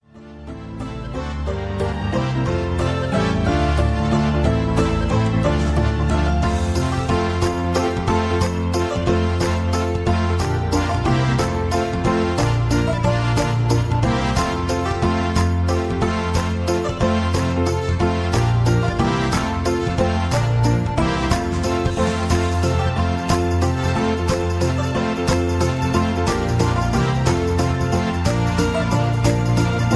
Tags: backing tracks , irish songs , karaoke , sound tracks